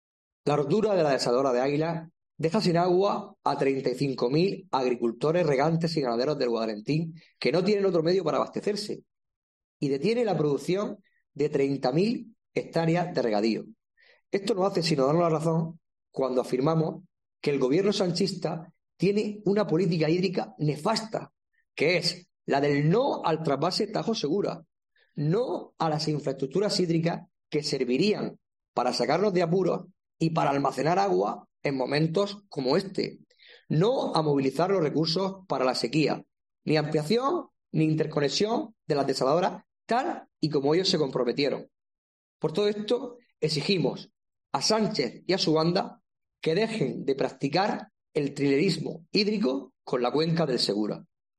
Jesús Cano, diputado del PP en Asamblea Regional